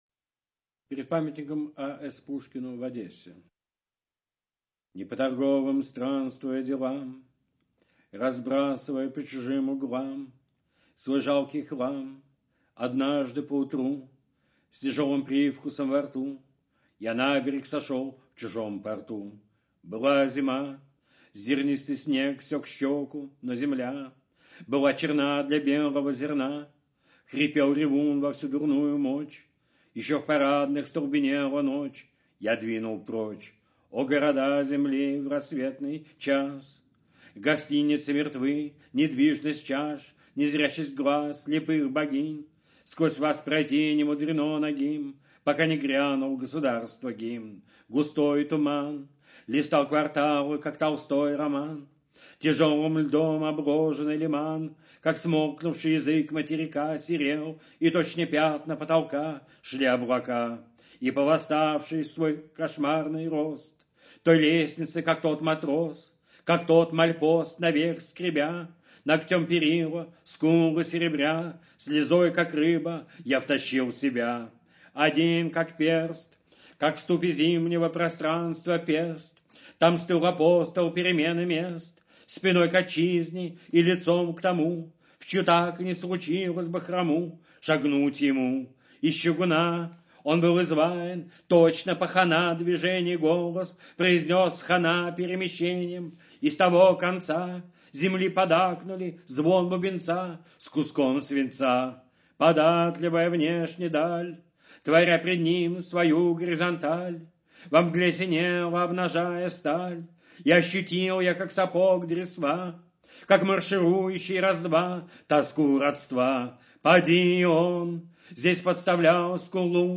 Brodskiy-Pered-pamyatnikom-Pushkinu-v-Odesse-chitaet-avtor-stih-club-ru.mp3